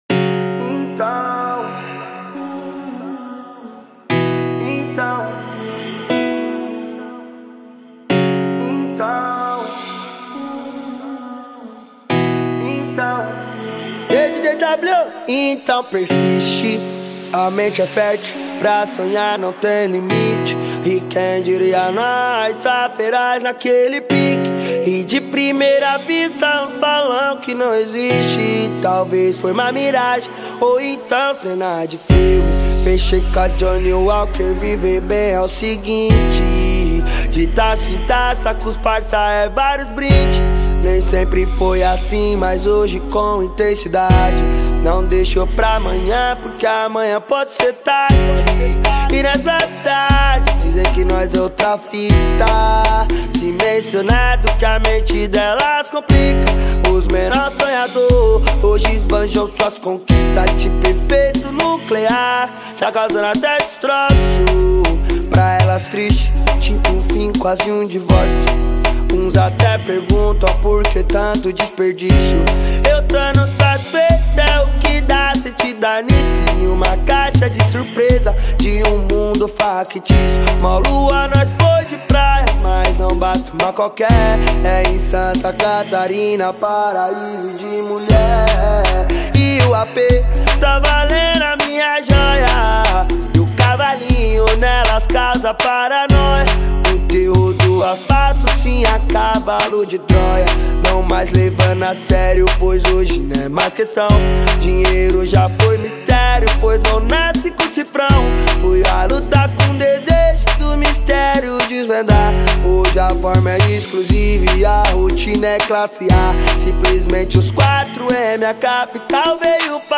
2024-02-16 22:28:30 Gênero: Funk Views